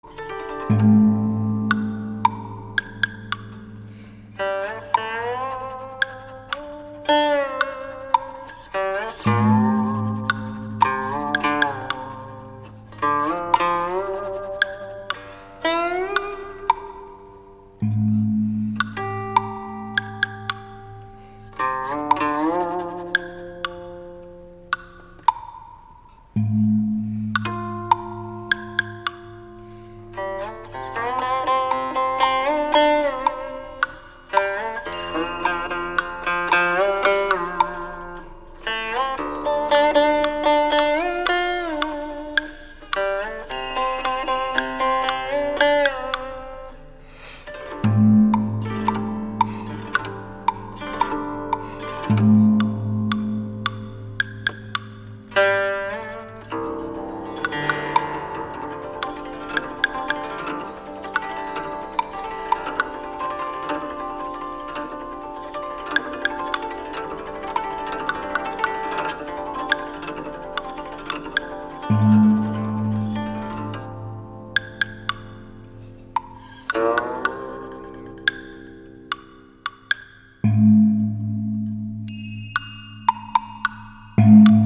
結合中國傳統打擊樂器與西方樂器的靈活編曲方式
專輯裏面的鼓樂曲目，兼具發燒的震撼力與優美的旋律性，以多元化的曲風，使整張唱片更具可聽性。